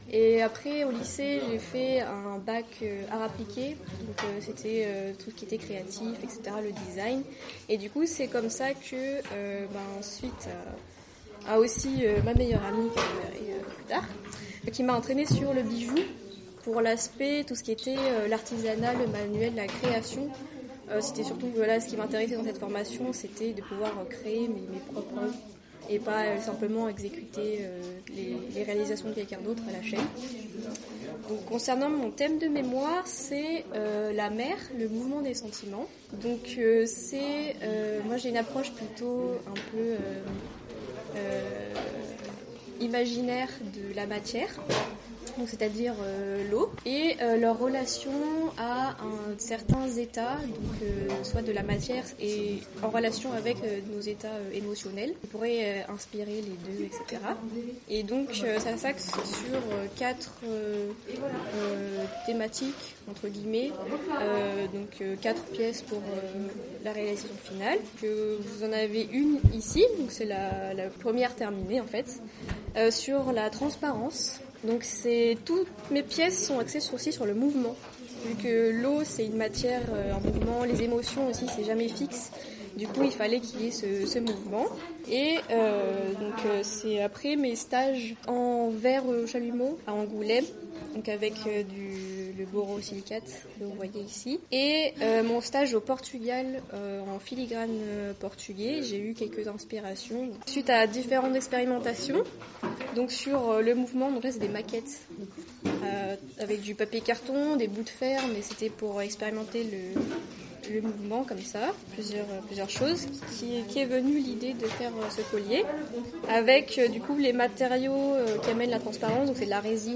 Afin de rendre compte de leur démarche, 7 étudiantes sur les 10 de cette 3e année sont interviewées dans le cadre de leur atelier et répondent chacune à 4 questions :
Un extrait de l’interview…